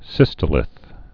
(sĭstə-lĭth)